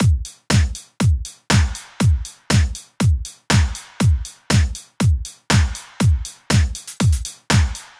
23 DrumLoop.wav